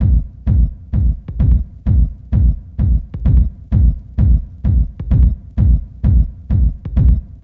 NRG 4 On The Floor 037.wav